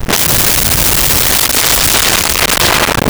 Explosion Deep Flare
Explosion Deep Flare.wav